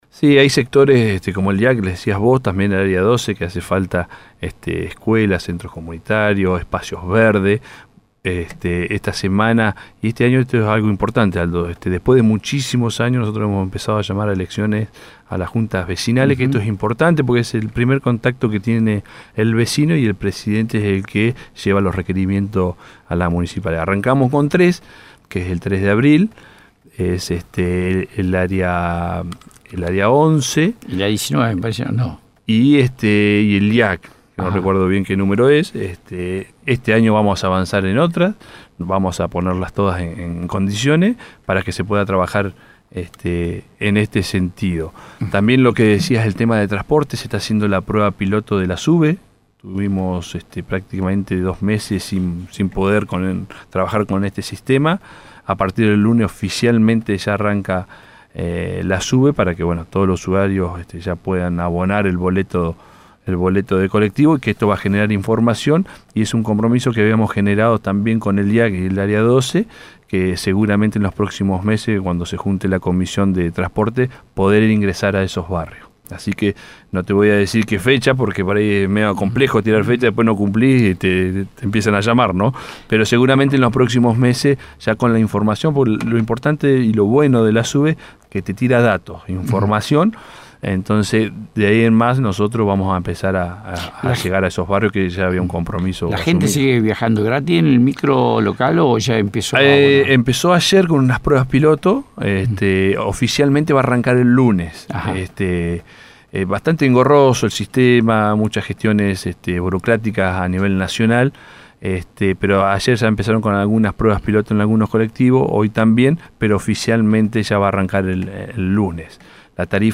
En comunicación con Bahía Engaño, el Secretario de Gobierno, Miguel Larrauri, habló acerca de diversos temas en el programa de Piedra Libre.
Entre otros temas, esto fue lo que expuso el Secretario de Gobierno: